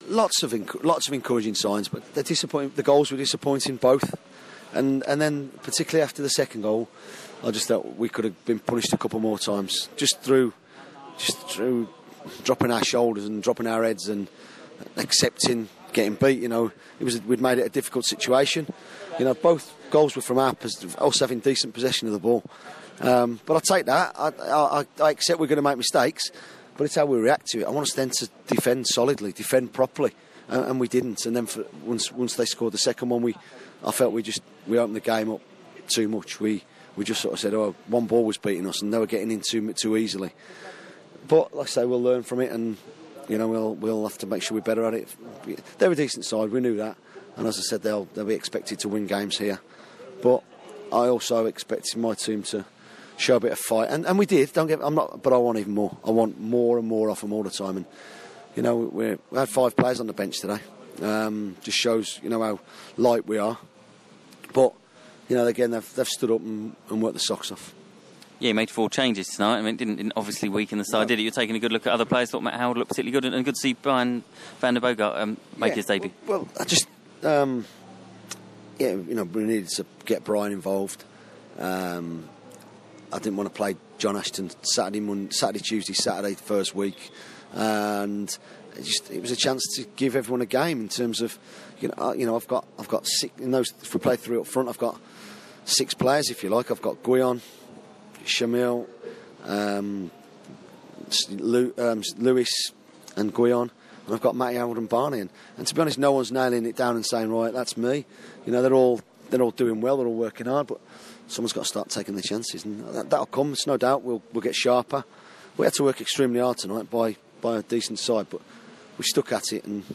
speaking to BBC Sussex after League Cup exit to Peterborough